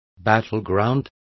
Complete with pronunciation of the translation of battleground.